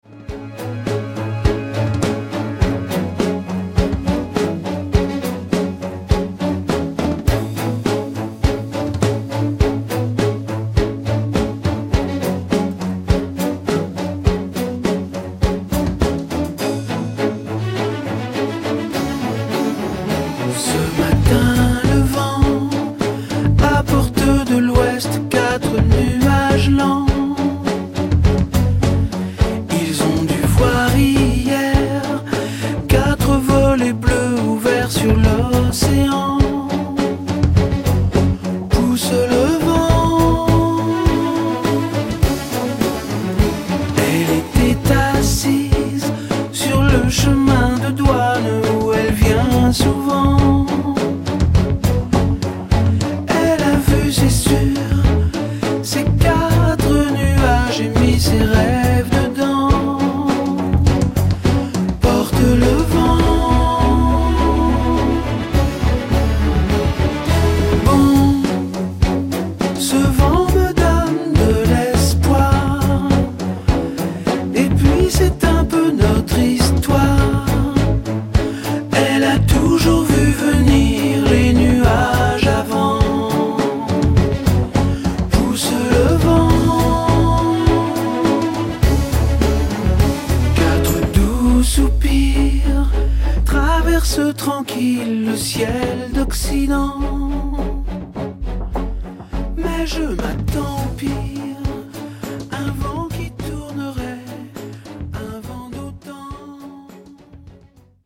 tonalité LA majeur